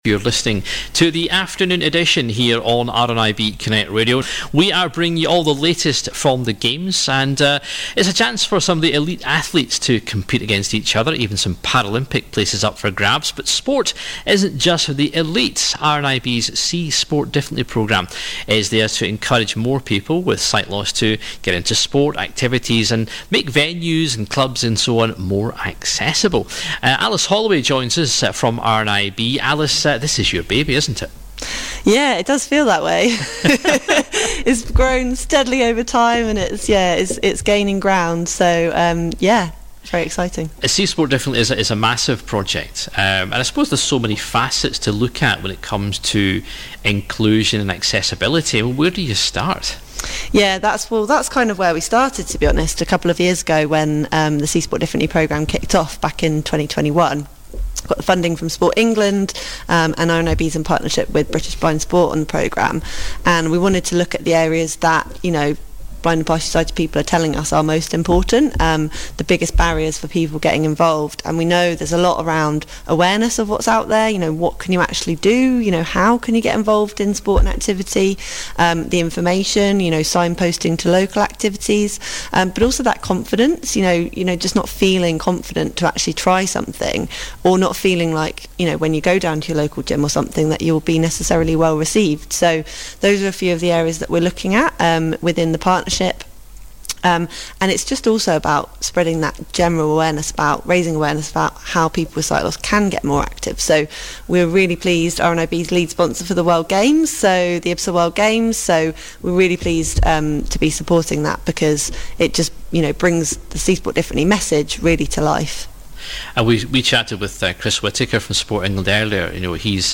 IBSA World Games 2023 - Afternoon Edition - Interviews